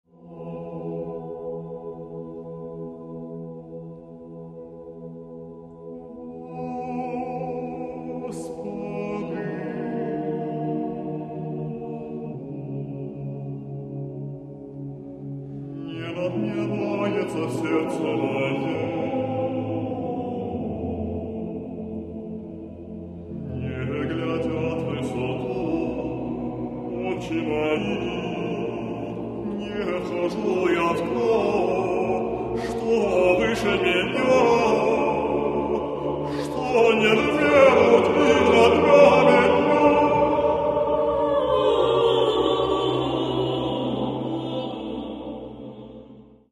Catalogue -> Classical -> Choral Art